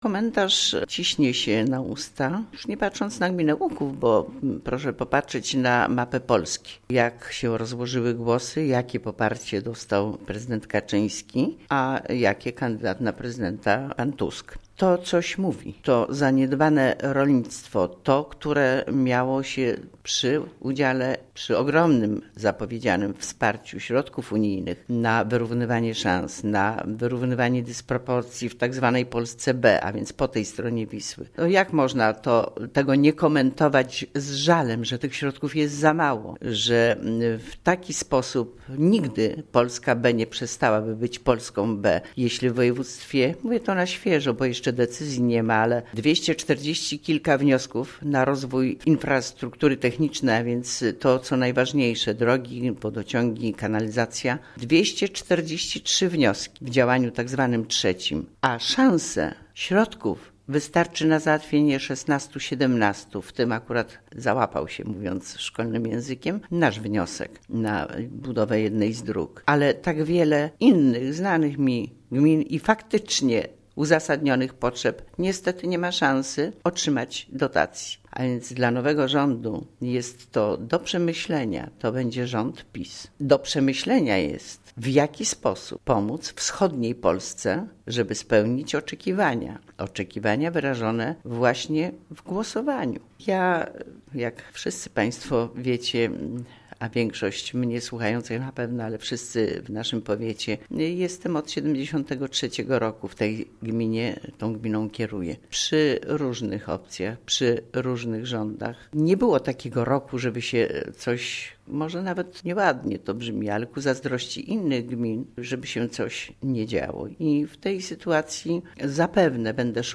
Komentuje: W�jt Gminy �uk�w Kazimiera Go�awska